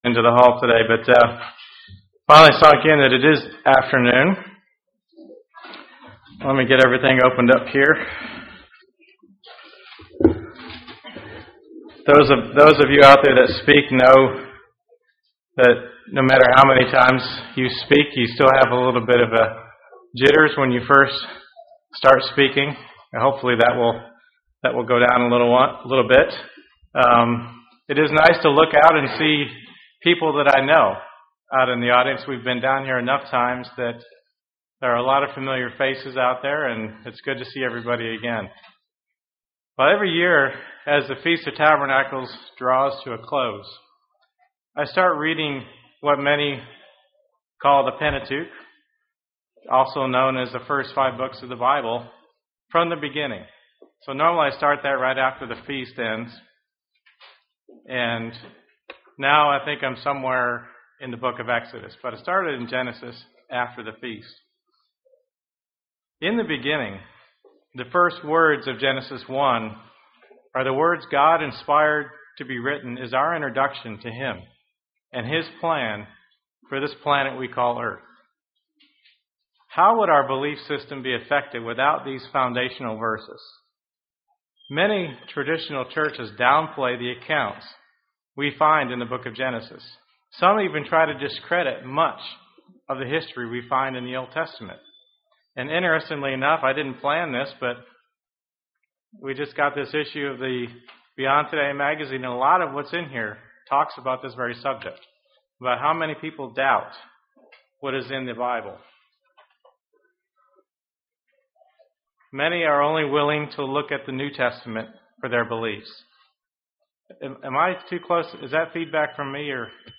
Sermons
Given in Huntsville, AL